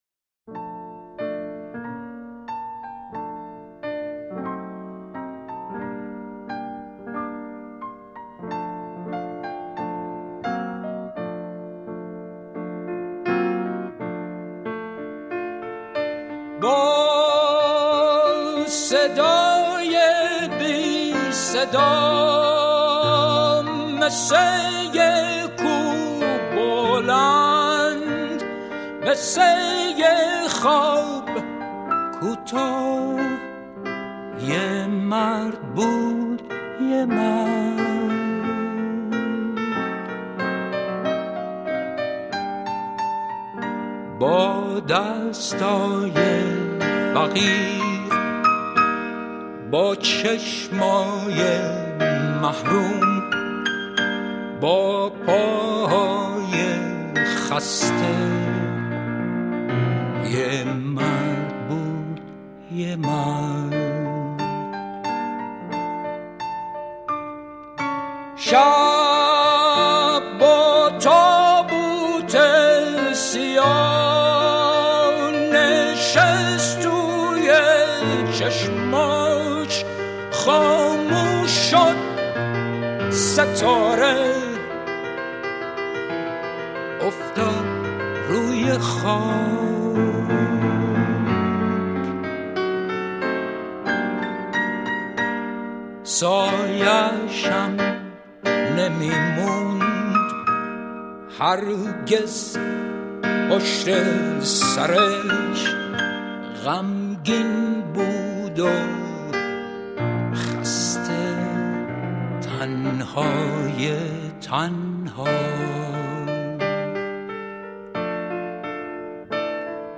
اهنگ قدیمی کافه ای